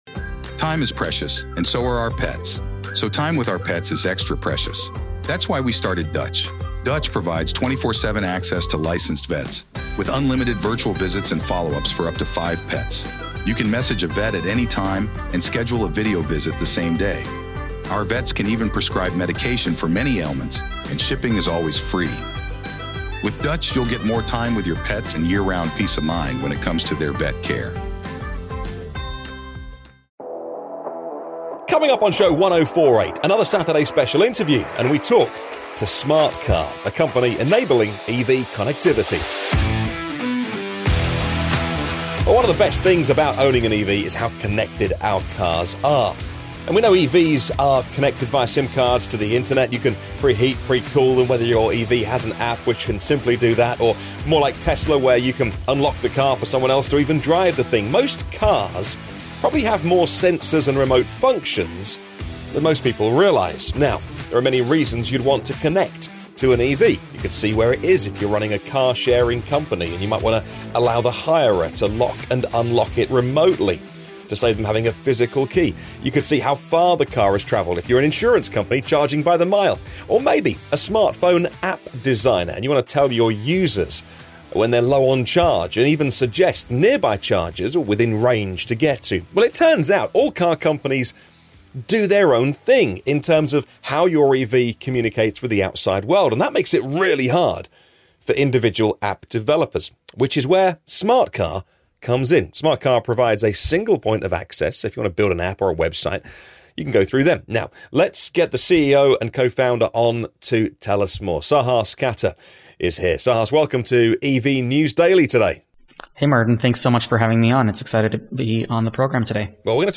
Coming up on show #1048 it's another Saturday Special interview, and we talk to Smartcar, a company enabling EV connectivity.